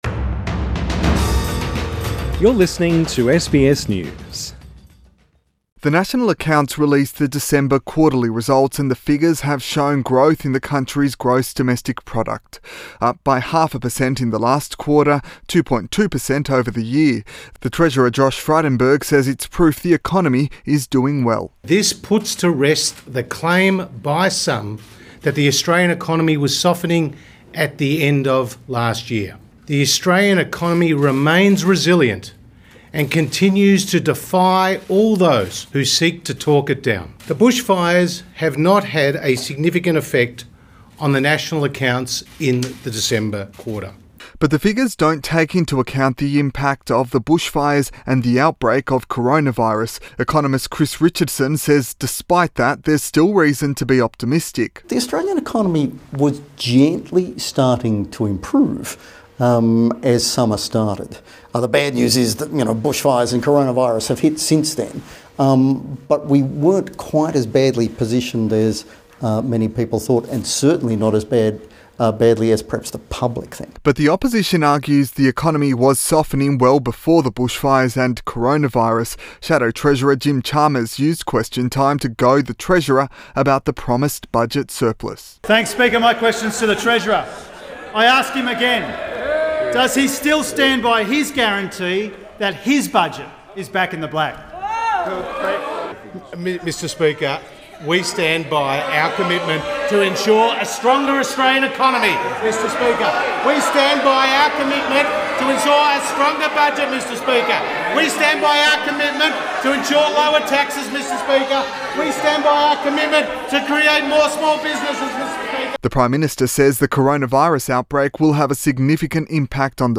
Federal Treasurer Josh Frydenberg speaks during a press conference Source: AAP